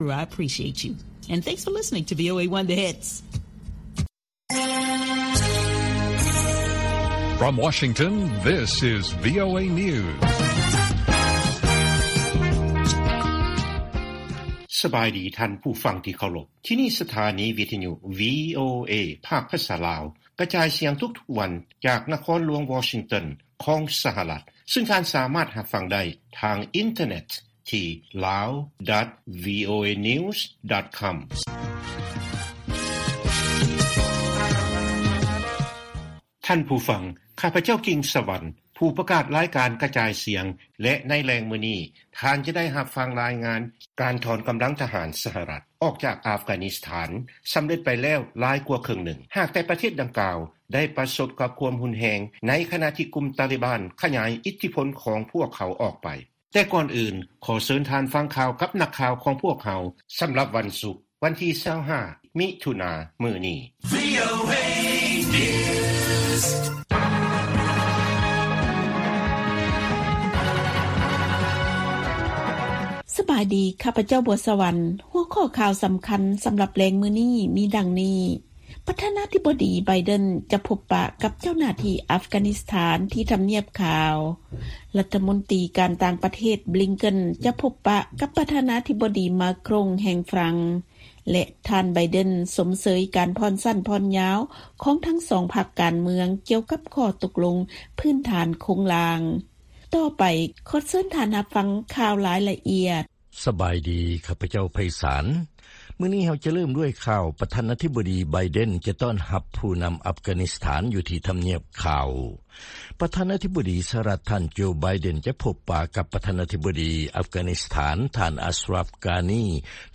ວີໂອເອພາກພາສາລາວ ກະຈາຍສຽງທຸກໆວັນ. ຫົວຂໍ້ຂ່າວສໍາຄັນໃນມື້ນີ້ມີ: 1) ສຫລ ເນລະເທດຄົນກັບໄປລາວ 219 ຄົນແລ້ວ ແລະຍັງອີກປະມານ 4,500 ຄົນ ທີ່ຢູ່ບັນຊີລໍຖ້າເນລະເທດ.